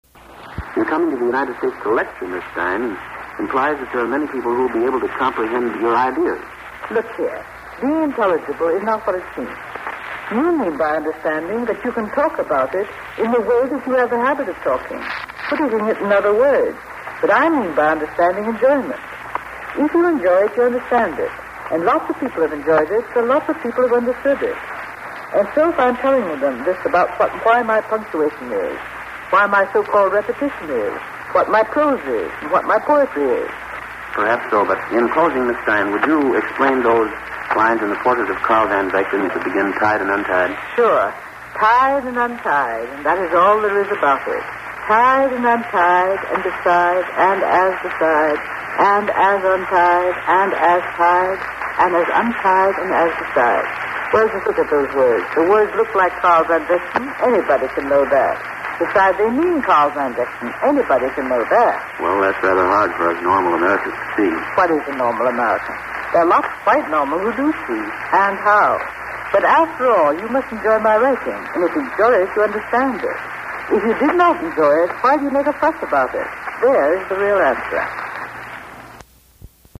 Interview with Stein
stein_interview.mp3